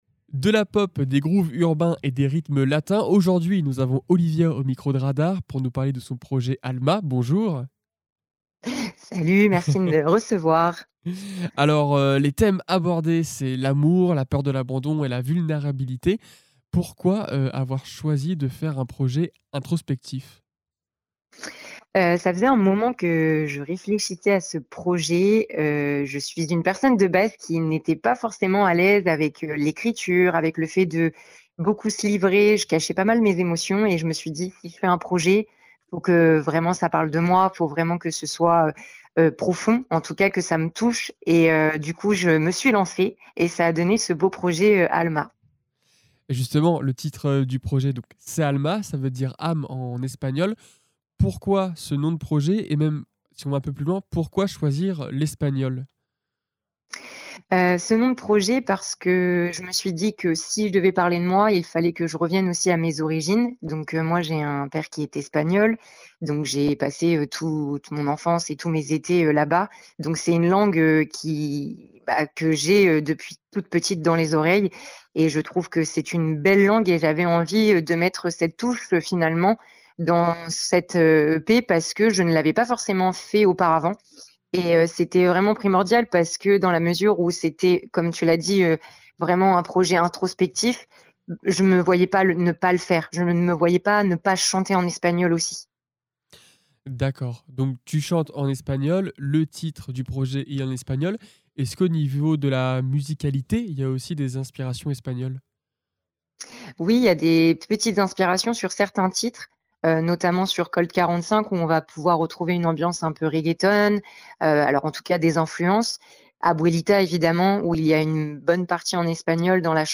Actu, sport, vie associative et bien plus encore : ici, vous retrouverez toutes les interviews produites par RADAR 🎙